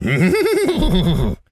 Animal_Impersonations
horse_neigh_calm_01.wav